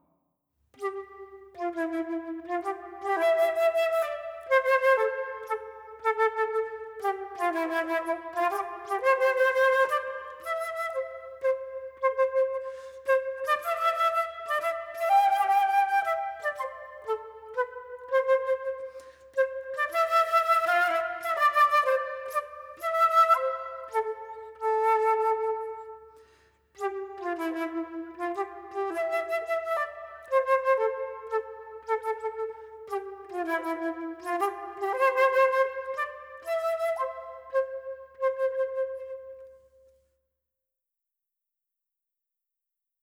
Dotted rhythms can sound childlike in their delivery if the rhythm is not handled clearly.